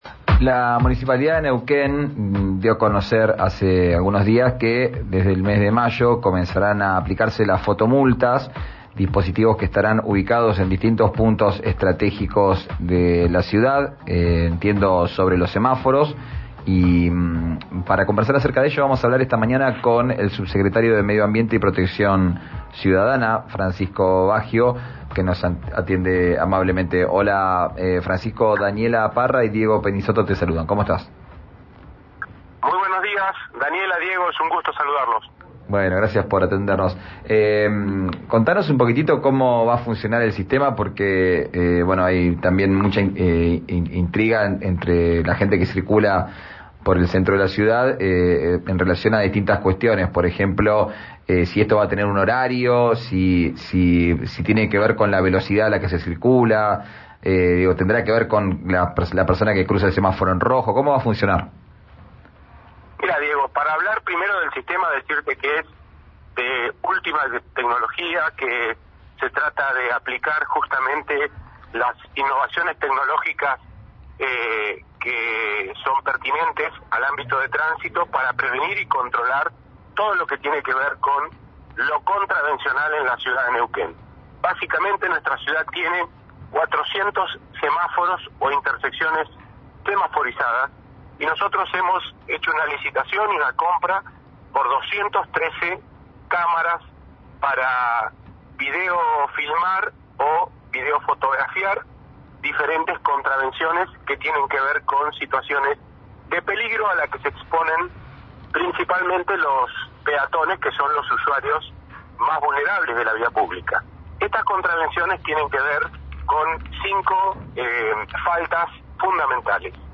Escuchá al subsecretario de Medio Ambiente y Seguridad Vial, Francisco Baggio en RÍO NEGRO RADIO:https